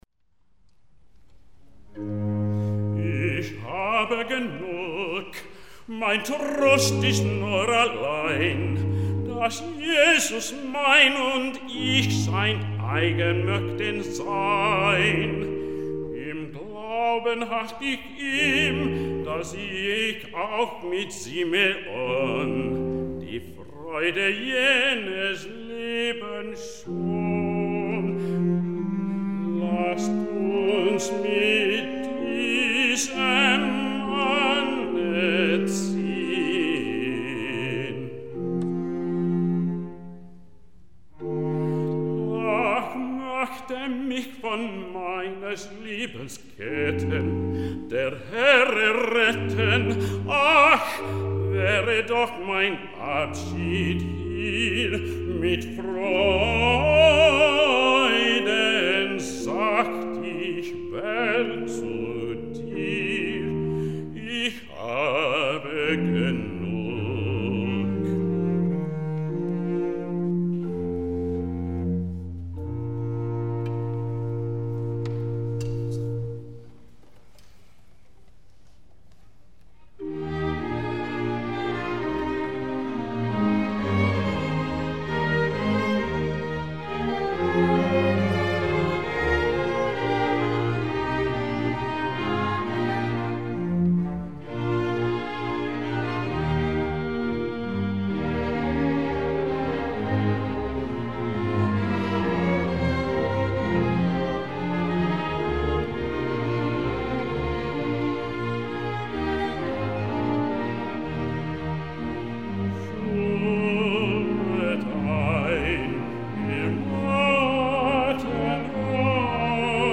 Verkið er samið fyrir bassasöngvara, óbó og strengjasveit með fylgirödd.
Tekið var upp í DSD sniði 1bit/5,644Mhz á Korg MR1000 og Sound Device 305, 3ja rása mixer. Notast var við tvo SE-4400a hljóðnema (cardioid) sem staðsettir voru í 2,5 metra hæð yfir miðri hljómsveitinni.
Það er fremur erfitt að ná góðum upptökum af heilli sinfóníuhljómsveit með aðeins þremur hljóðnemum, ekki síst þegar hljómsveitinni er ekki stillt upp fyrir þessa þrjá hljóðnema. Hér tekst þó hæfilega vel til.